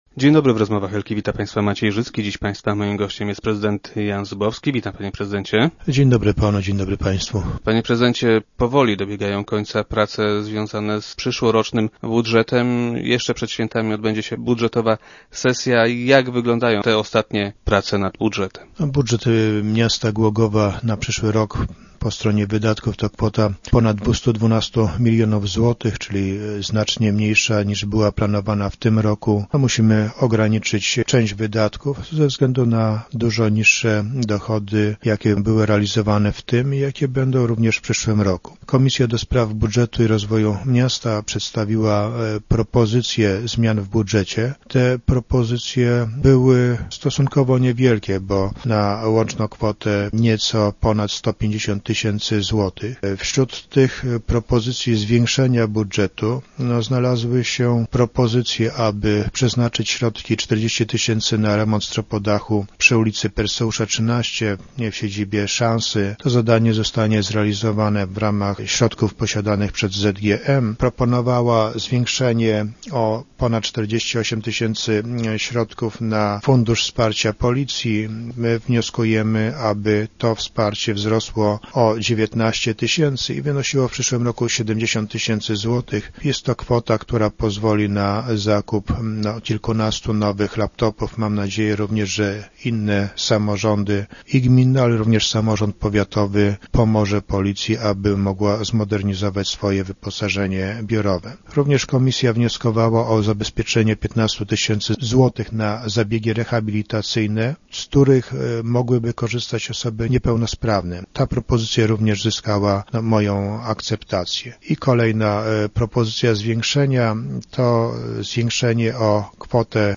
Tak zapewnił prezydent Jan Zubowski, który był dziś gościem Rozmów Elki. Władze miasta postanowiły przychylić się do apelu mieszkańców osiedla Piastów Śląskich i zainstalować sygnalizację świetlną na przejściu prowadzącym między innymi do szkoły.